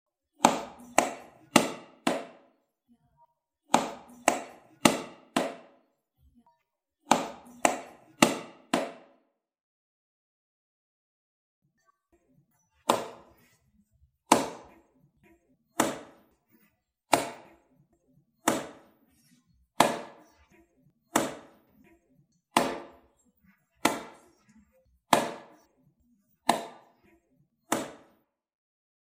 Tiếng Vỗ tay theo Phách, Nhịp (Mầm non)
Thể loại: Tiếng động
tieng-vo-tay-theo-phach-nhip-mam-non-www_tiengdong_com.mp3